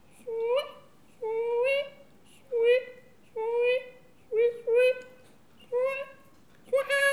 bruit-animal_26.wav